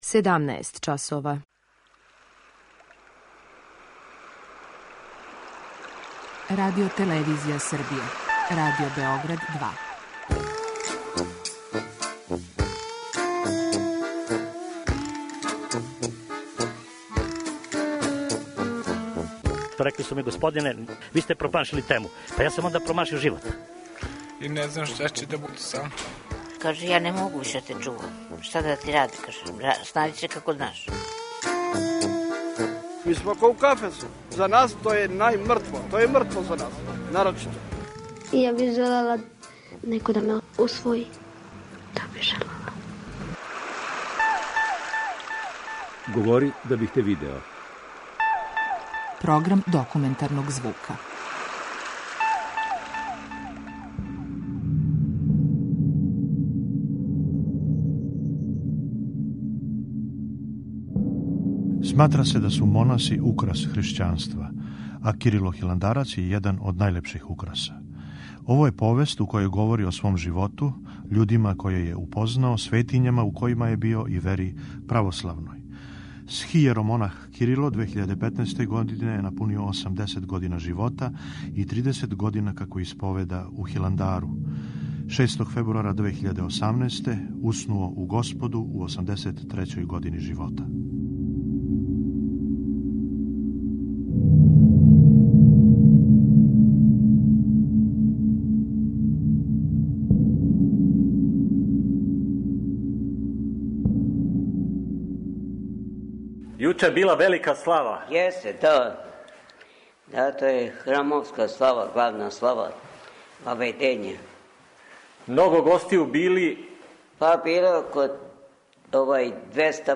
Документарни програм
преузми : 10.76 MB Говори да бих те видео Autor: Група аутора Серија полусатних документарних репортажа, за чији је скупни назив узета позната Сократова изрека: "Говори да бих те видео". Ова оригинална продукција Радио Београда 2 сједињује квалитете актуелног друштвеног ангажмана и култивисане радиофонске обраде.